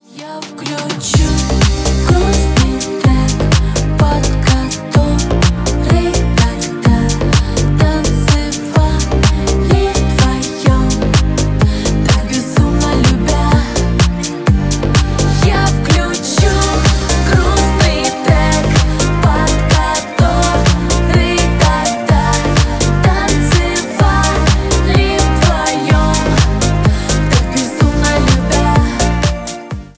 Ремикс
клубные # грустные